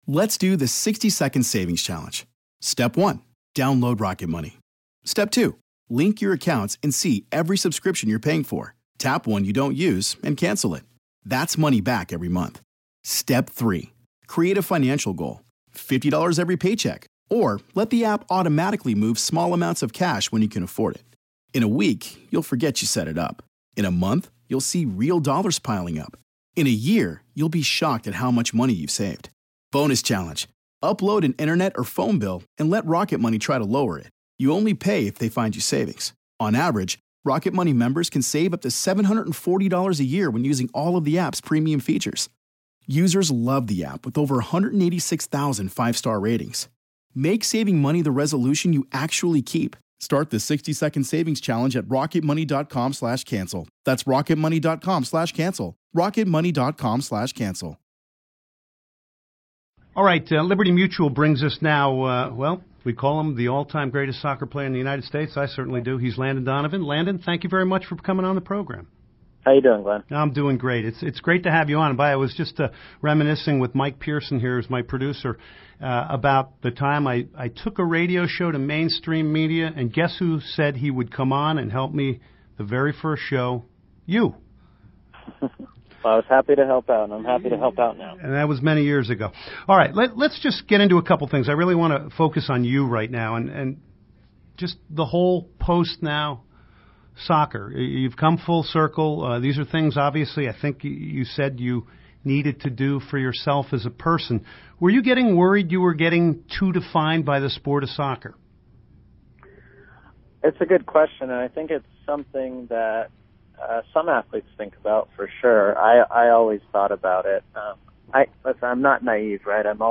Landon Donovan Interview